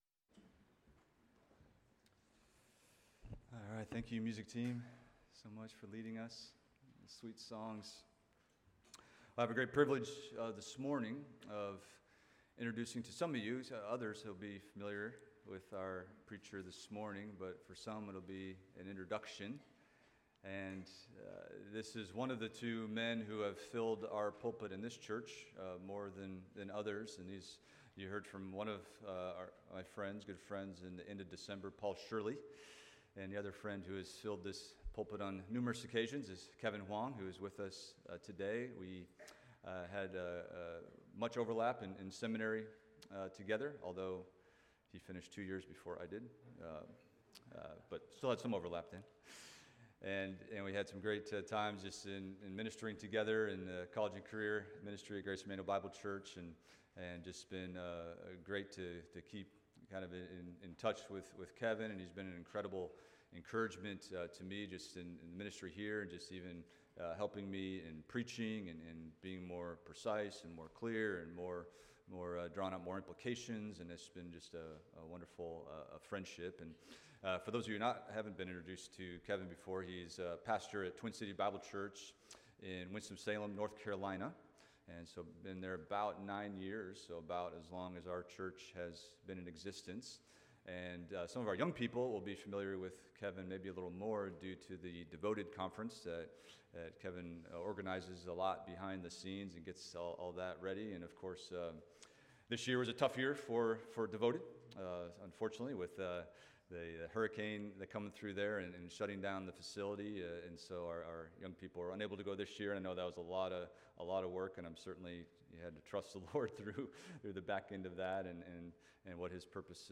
Expository Preaching from The Psalms – Psalm 107 – How to Appropriately Respond to God’s Grace